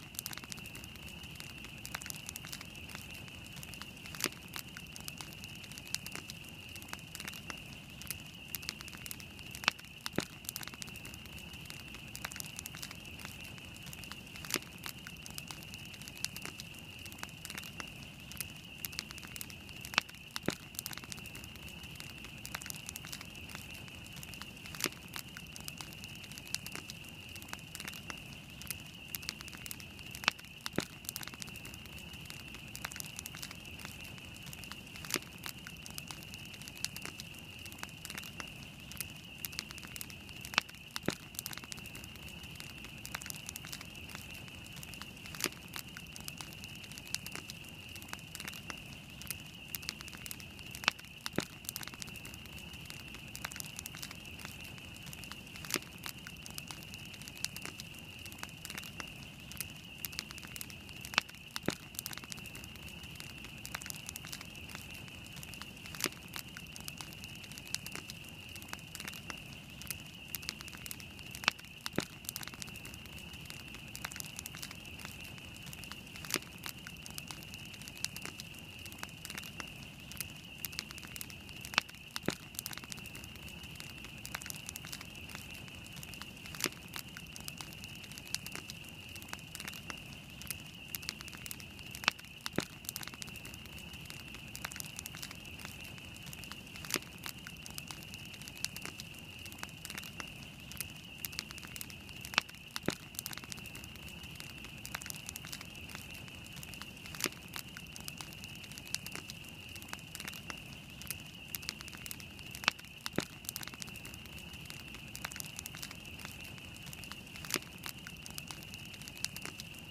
Feu.mp3